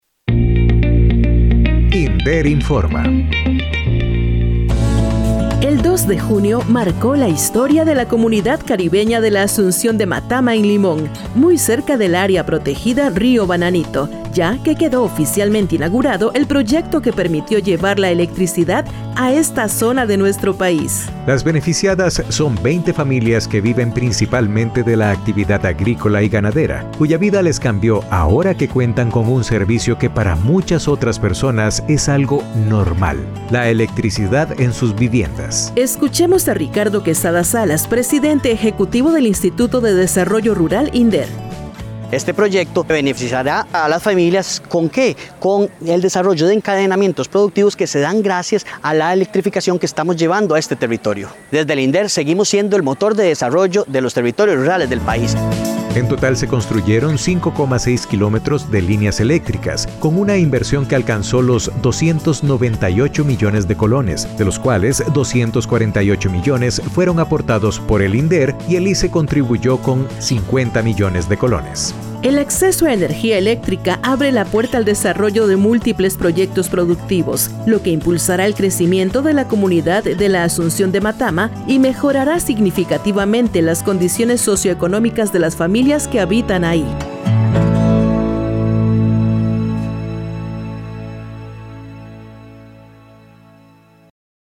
Escuchemos a Ricardo Quesada Salas, presidente ejecutivo del Instituto de Desarrollo Rural, INDER,